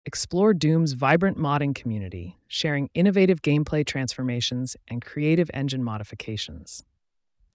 Get the essence of any website delivered in a crisp, engaging audio introduction.